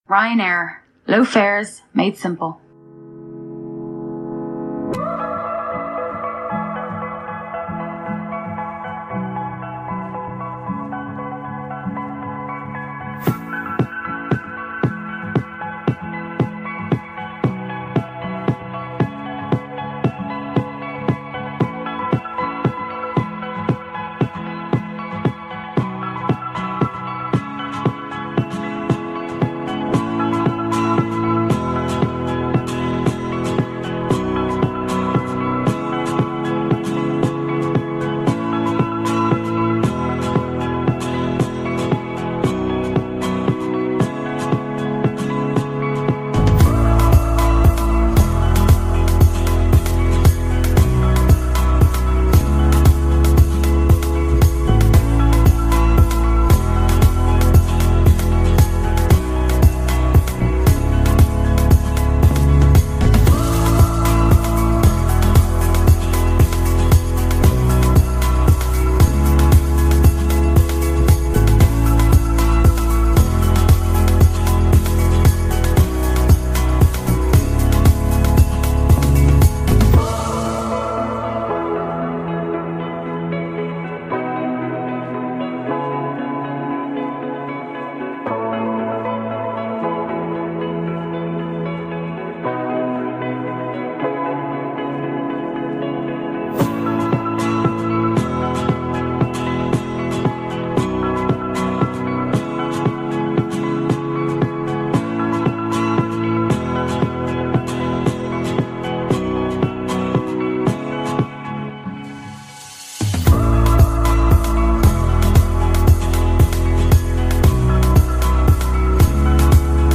BoardingMusic[1][Afternoon].ogg